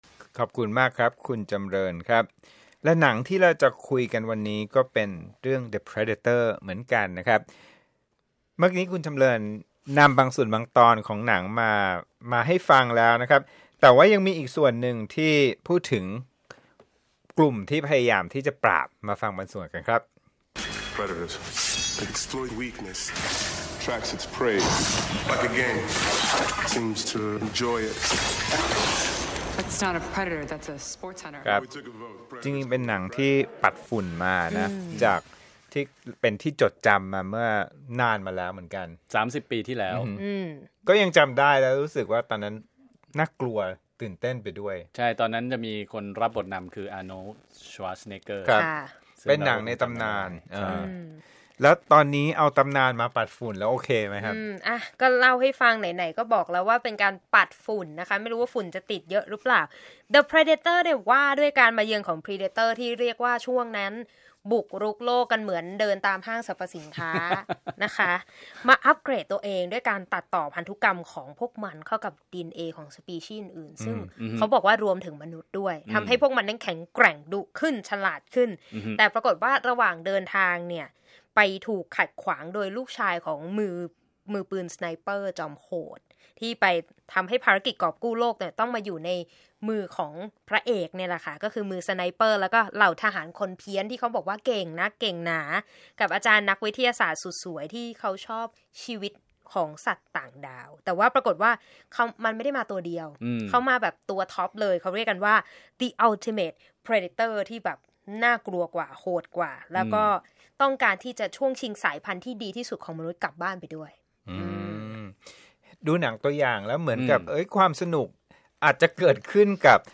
(รับฟังวีโอเอไทยคุยหนัง Predator 2018 จากส่วนหนึ่งของรายการสุดสัปดาห์กับวีโอเอ ออกอากาศทุกเช้าวันเสาร์ ตามเวลาประเทศไทย)